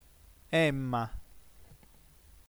dodicesima lettera dell’alfabeto sardo; esprime il suono consonantico nasale bilabiale sonoro, in caratteri IPA [m]) all’inizio e in corpo di parola sia in nessi con altre consonanti sia in posizione intervocalica, in derivazione dalla M latina o in prestiti (M-, -M-, -LM-, -RM-, -SM-, -XM-, -DM-, -MP-, -MB-: mari, fumu, parma, forma, asma, smucai, amirai, campu, ambaduus) o dalla -N- del nesso latino -NV- (imbentai).